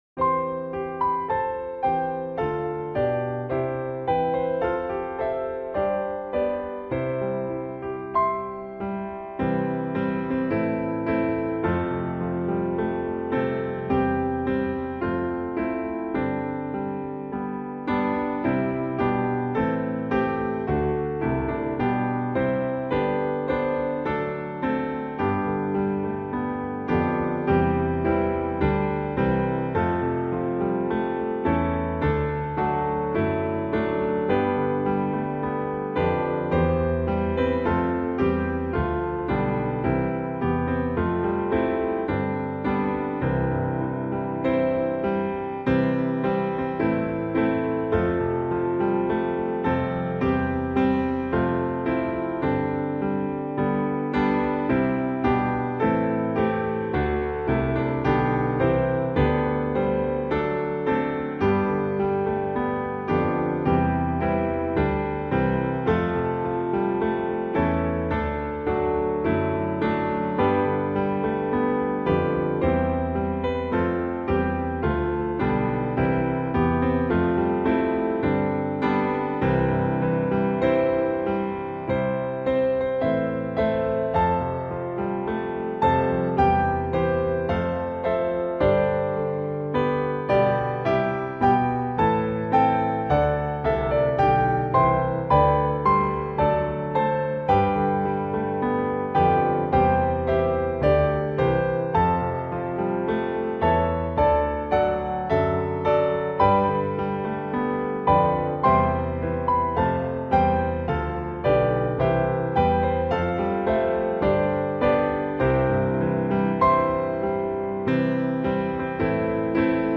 piano instrumental hymn